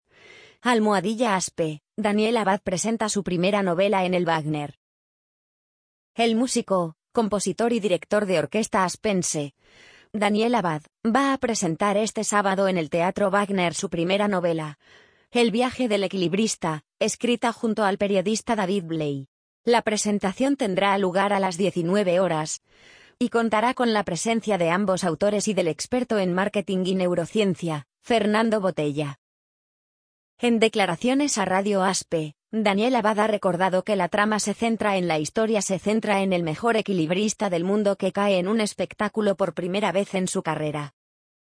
amazon_polly_34554.mp3